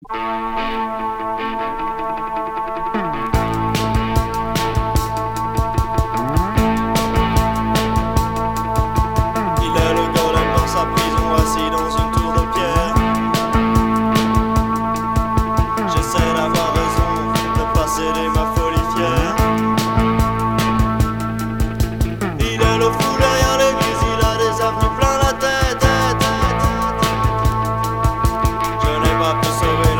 Cold wave punk